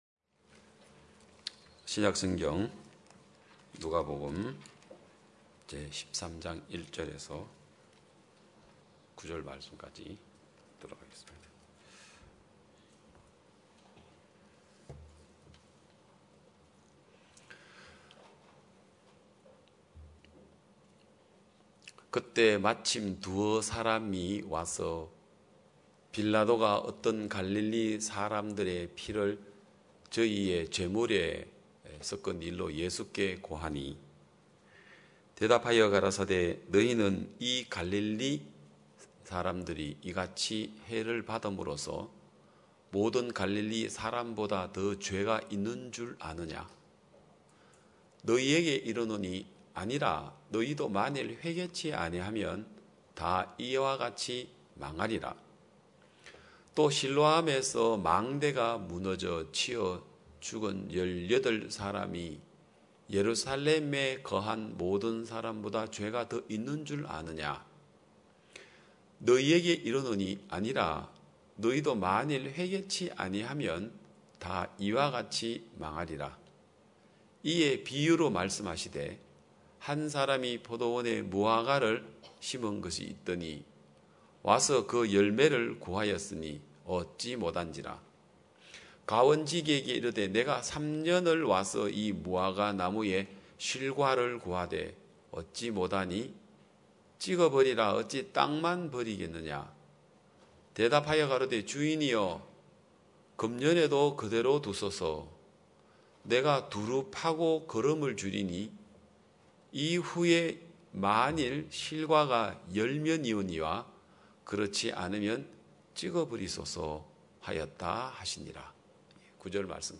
2021년 6월 20일 기쁜소식양천교회 주일오전예배
성도들이 모두 교회에 모여 말씀을 듣는 주일 예배의 설교는, 한 주간 우리 마음을 채웠던 생각을 내려두고 하나님의 말씀으로 가득 채우는 시간입니다.